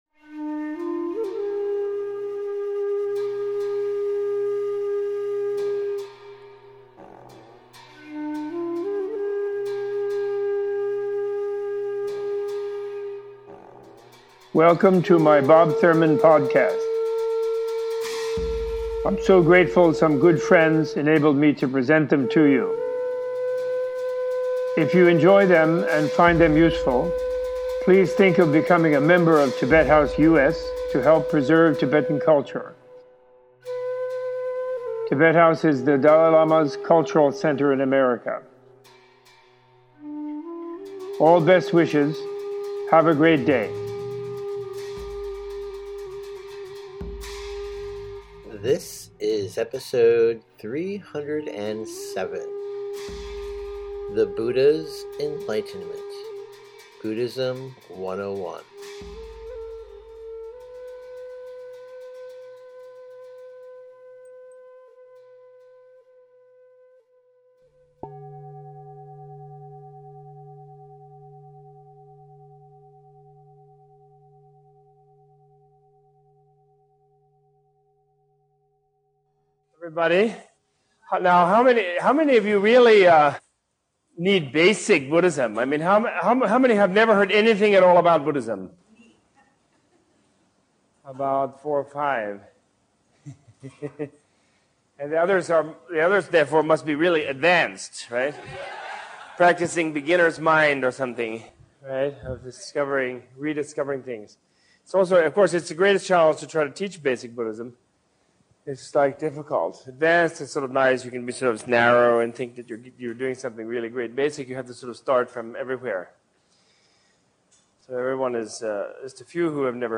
In this episode Robert Thurman re-tells Shakyamuni Buddha’s life story and gives an in-depth teaching on the nature of enlightenment for those of all backgrounds, faiths and traditions.
-Text From Better Listen Basic Buddhism This episode is an excerpt from the Better Listen "Basic Buddhism" Audio Course.